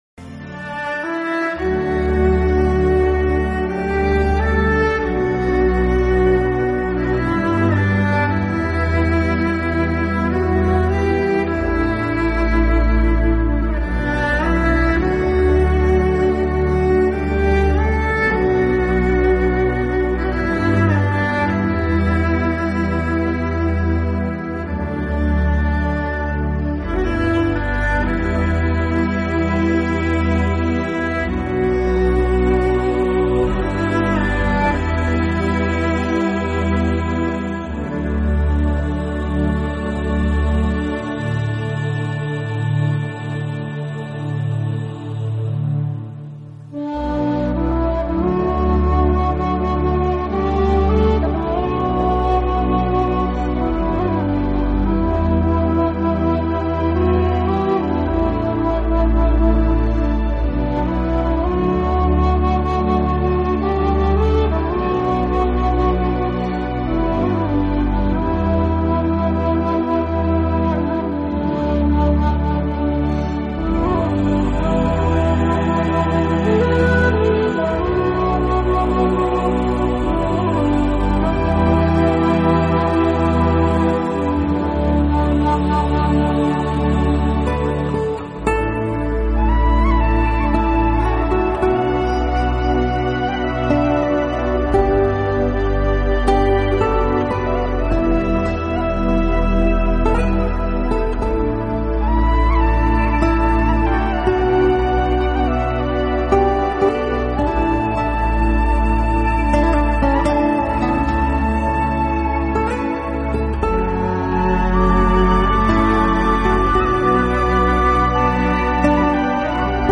专辑语言：纯音乐
这种音乐是私密的，轻柔的，充满庄严感并总是令人心胸开阔。